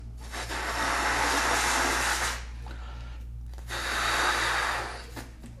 blowinguptheballoon
ballon inflation sound effect free sound royalty free Memes